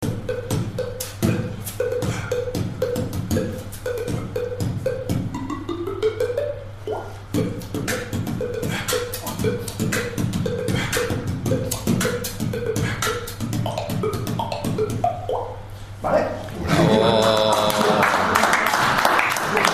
beatbox
efectos musicales formato MP3 audio(0,15 MB).